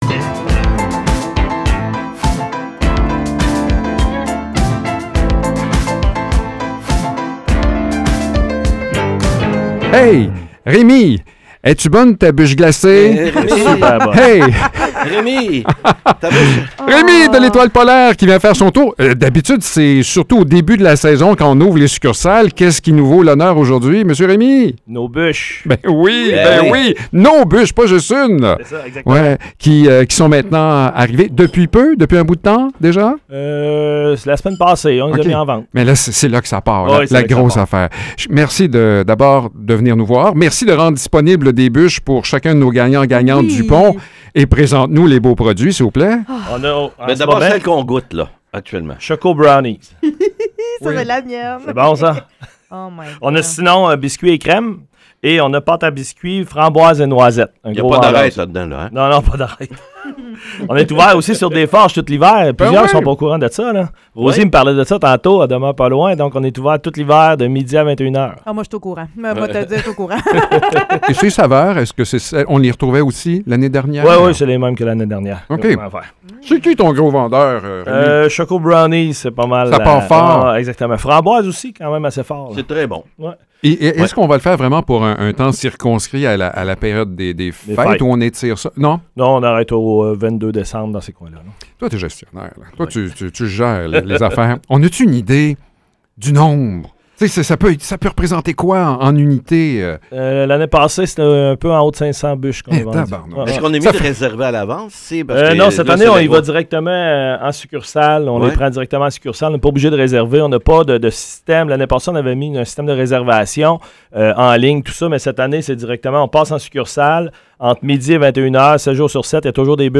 Entrevue avec l’Étoile polaire